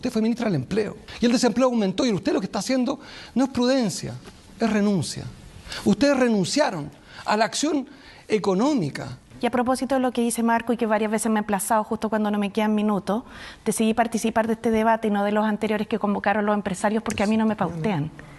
Los dardos cruzados que dejó primer debate presidencial televisivo